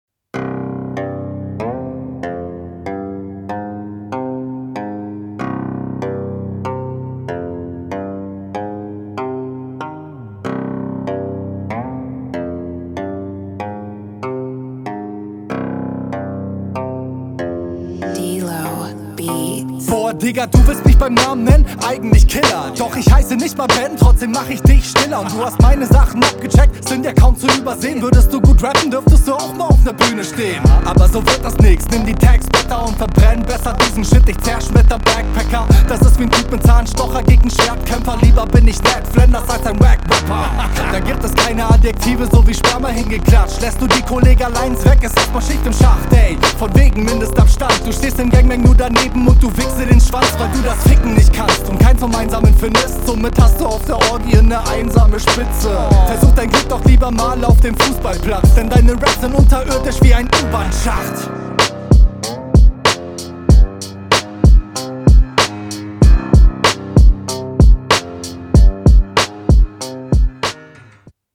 Mehr Druck.